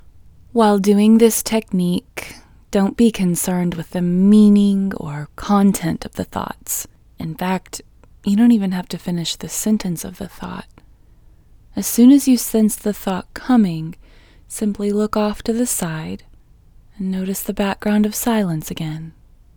QUIETNESS Female English 10
Quietness-Female-10-1.mp3